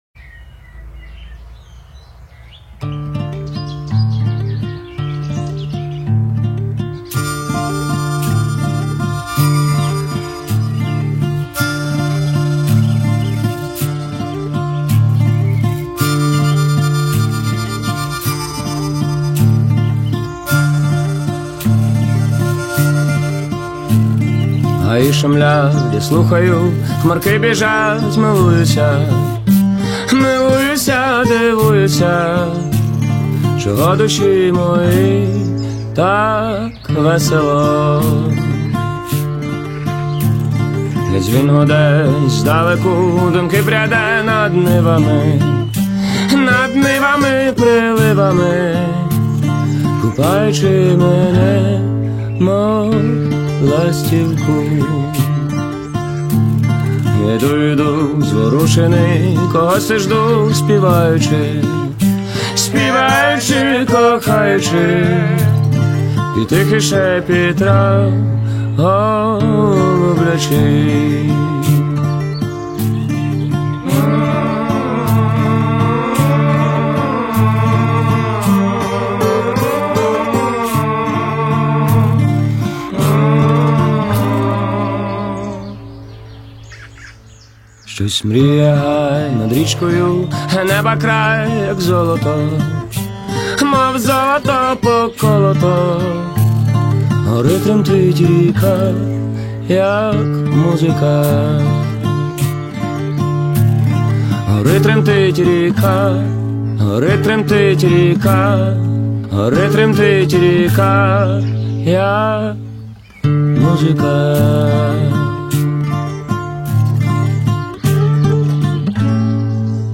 • Жанр: Folk